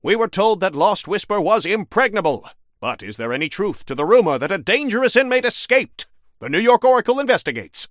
Newscaster_headline_67.mp3